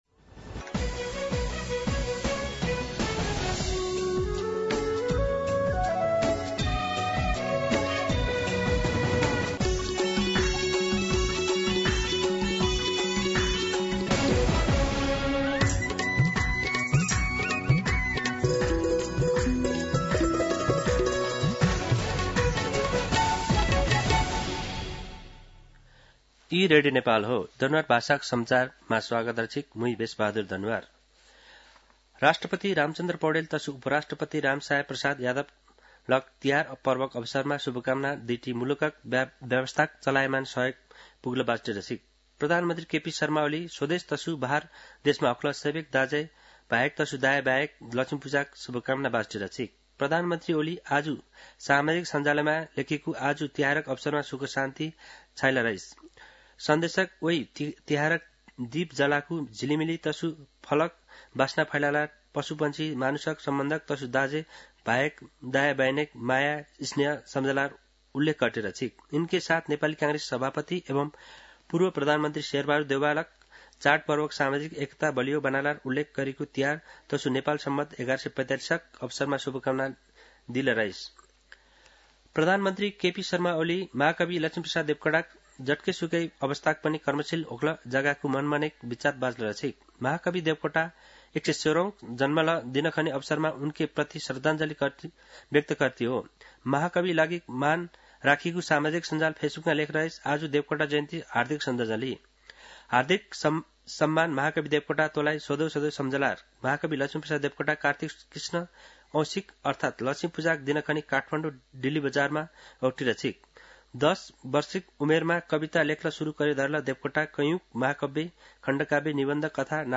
दनुवार भाषामा समाचार : १६ कार्तिक , २०८१
Danuwar-News-15.mp3